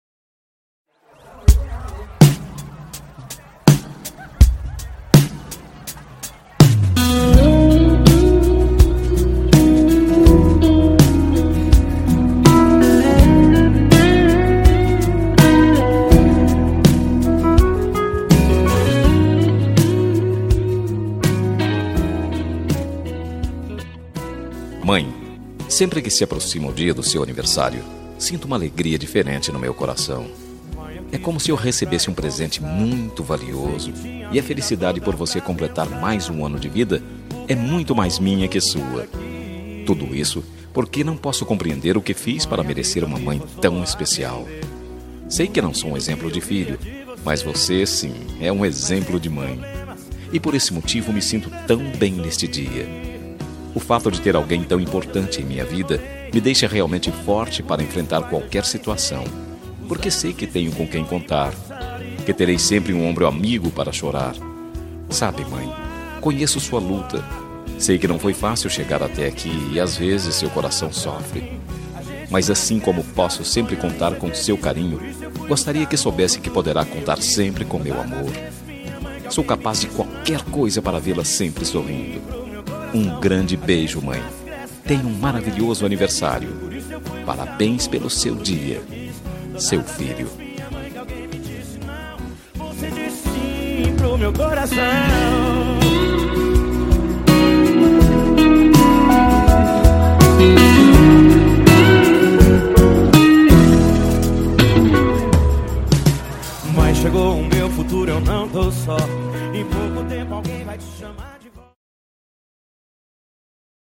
Aniversário de Mãe – Voz Masculina – Cód: 035402